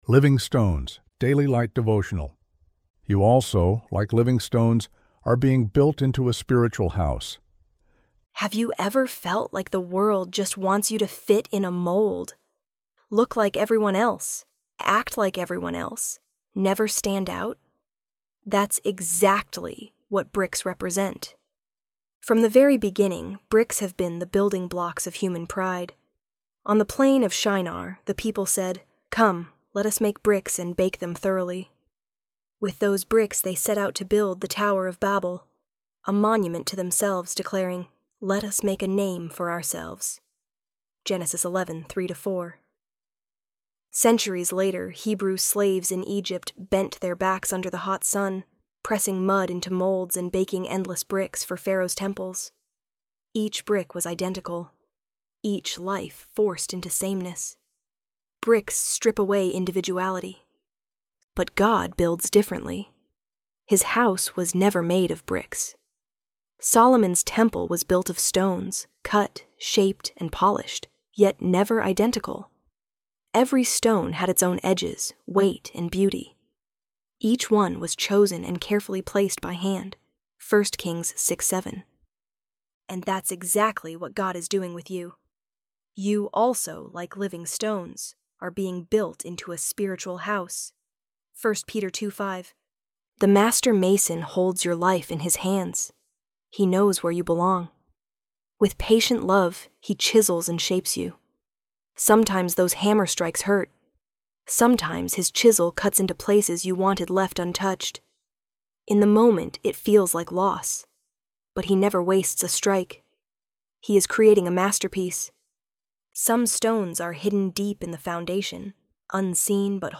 Daily Light Devotional